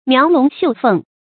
描龍繡鳳 注音： ㄇㄧㄠˊ ㄌㄨㄙˊ ㄒㄧㄨˋ ㄈㄥˋ 讀音讀法： 意思解釋： 指精美的手工刺繡。